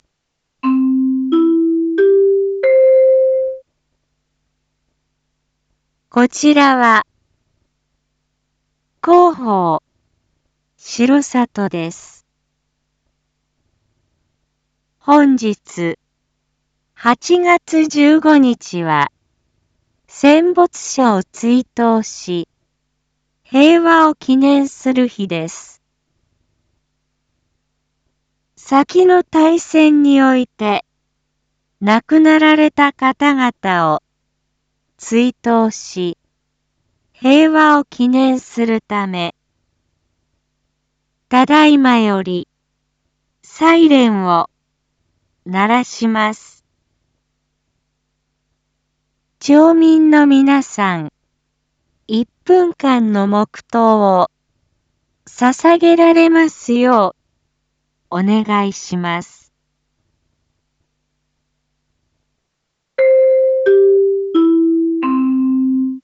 一般放送情報
先の大戦において亡くなられた方々を追悼し、平和を祈念するため、 ただいまより、サイレンを鳴らします。 町民の皆さん、一分間の黙とうを捧げられますようお願いします。